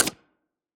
PickItem.ogg